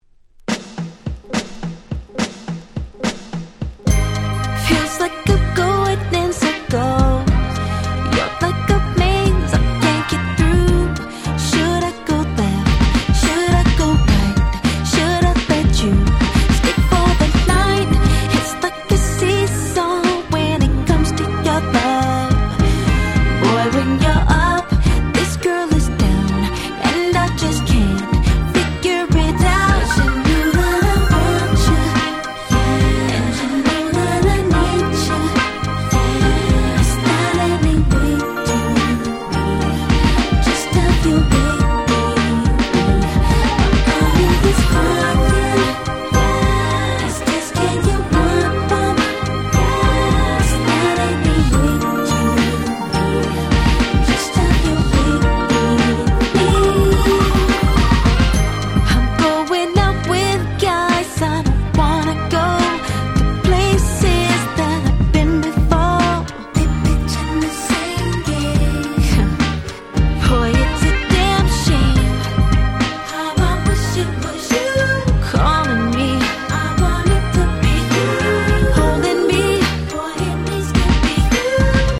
04' Smash Hit R&B.